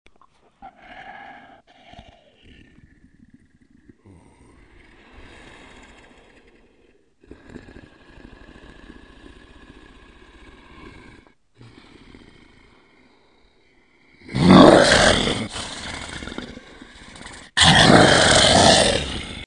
Download Werewolf sound effect for free.